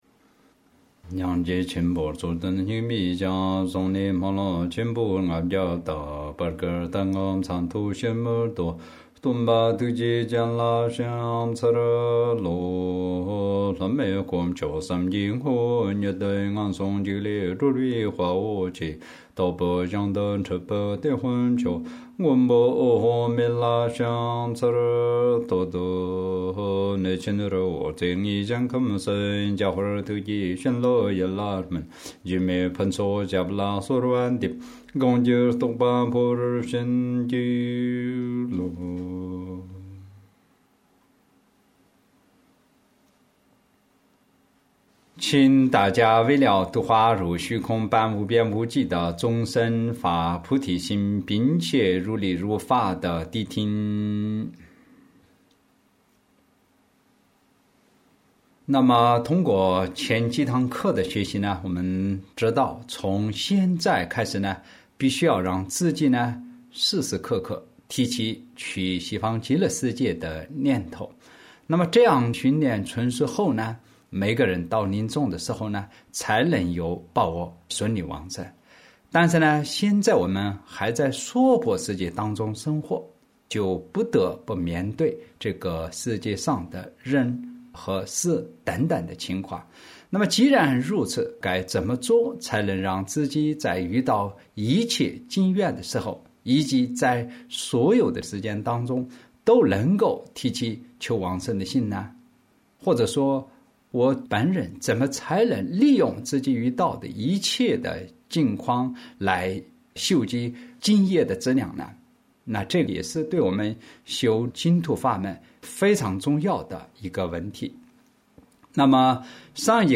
有聲書範本(主) - 正法網